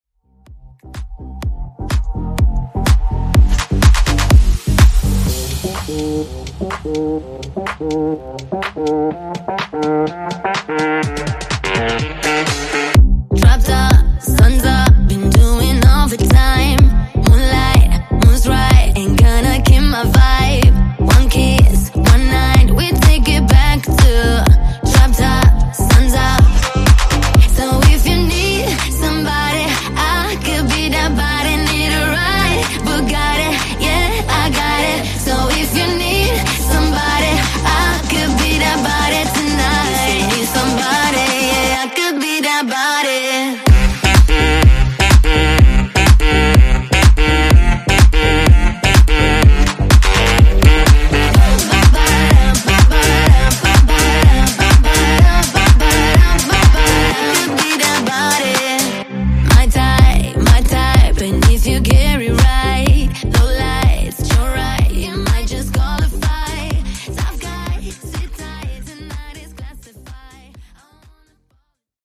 Genres: R & B , RE-DRUM Version: Dirty BPM: 97 Time